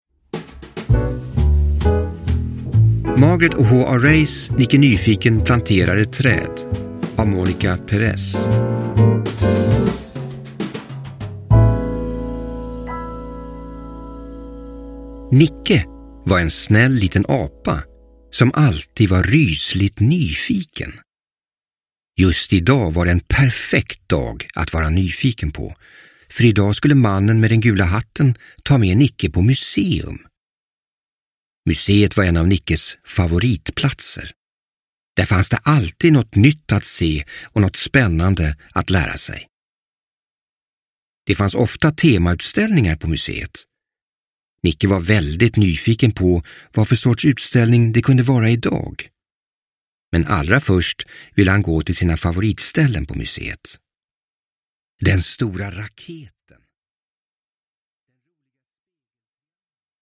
Nicke Nyfiken planterar ett träd – Ljudbok – Laddas ner